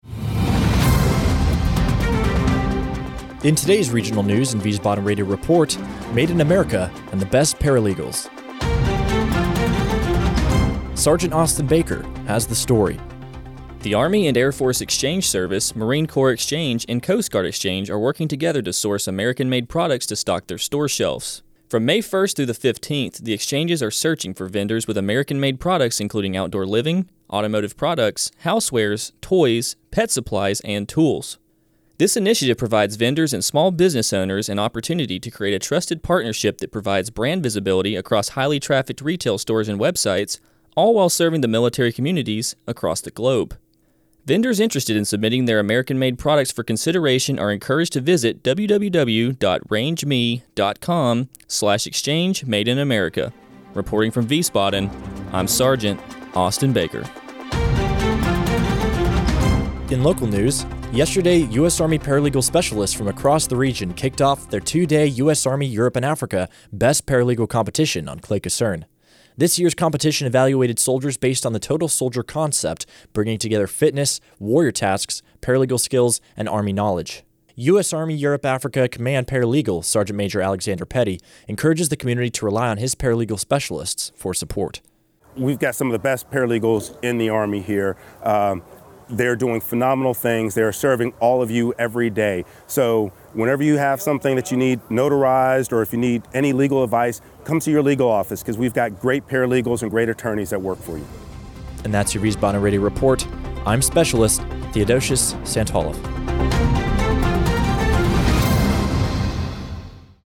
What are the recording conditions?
The audio product was recorded at the AFN Wiesbaden studio on Clay Kaserne, May 3, 2023.